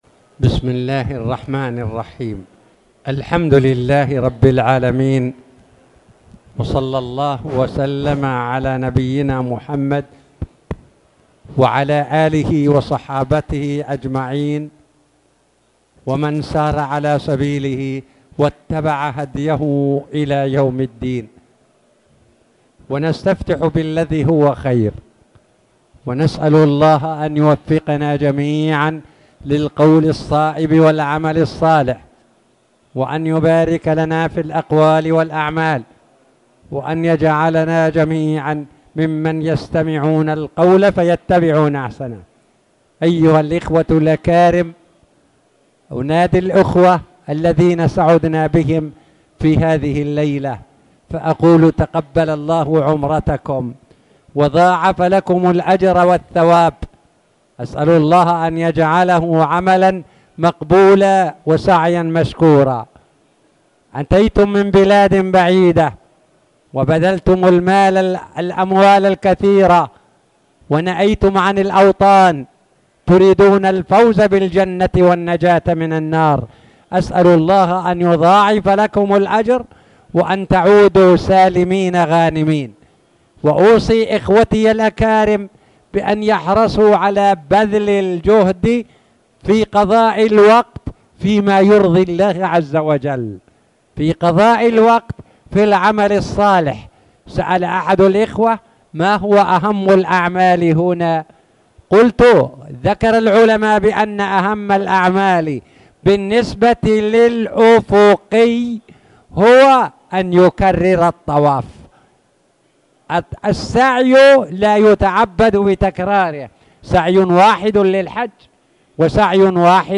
تاريخ النشر ١٥ رجب ١٤٣٨ هـ المكان: المسجد الحرام الشيخ